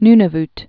(nnə-vt)